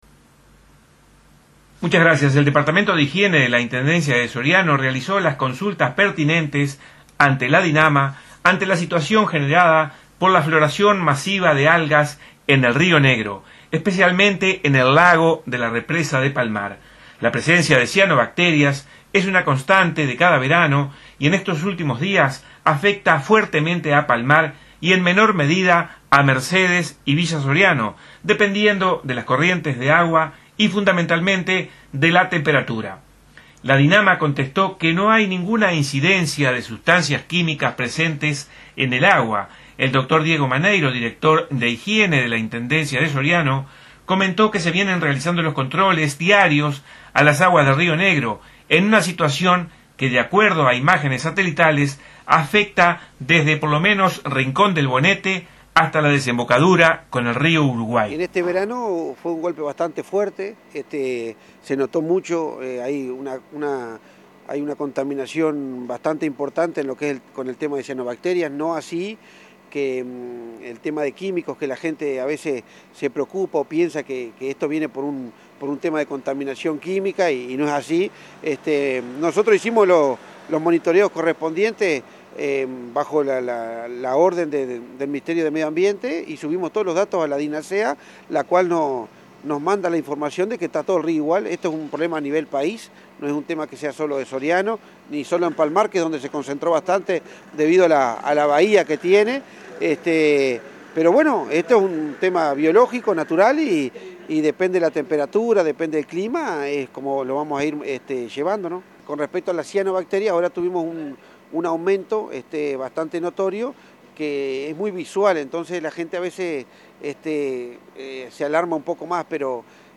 Desde Soriano, informó el corresponsal